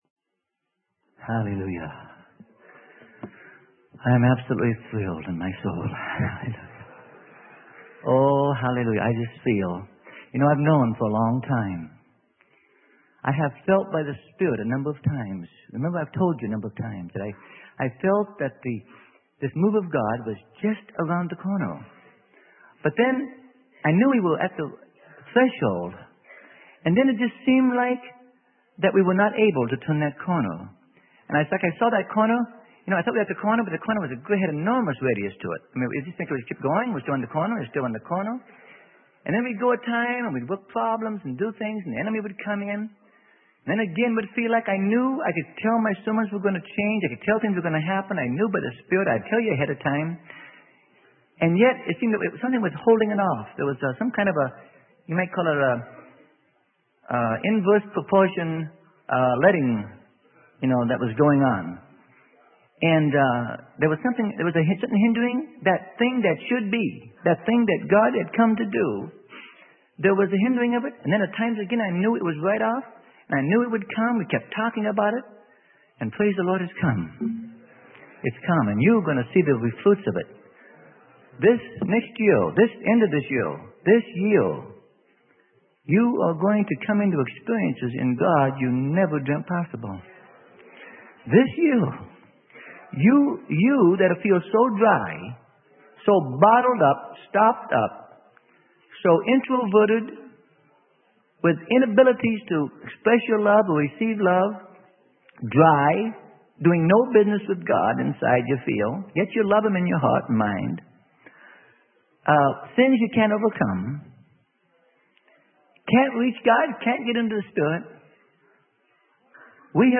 Sermon: The Progressive Move of God's Spirit - Freely Given Online Library